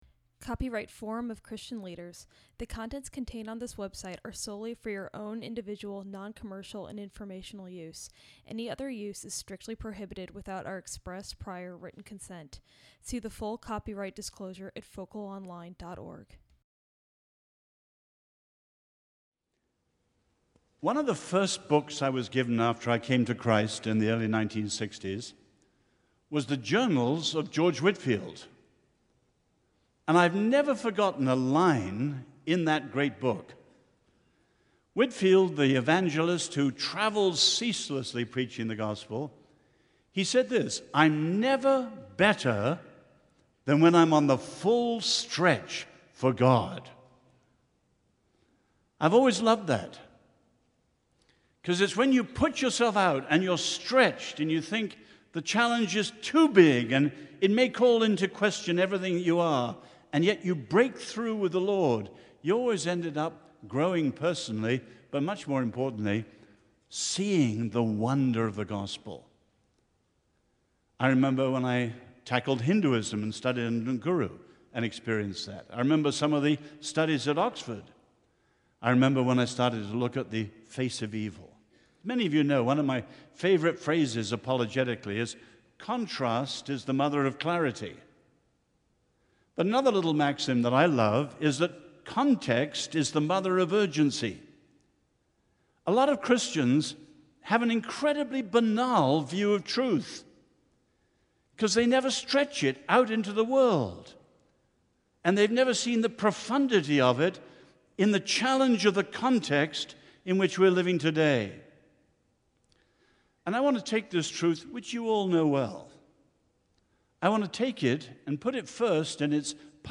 Event: ELF Plenary Session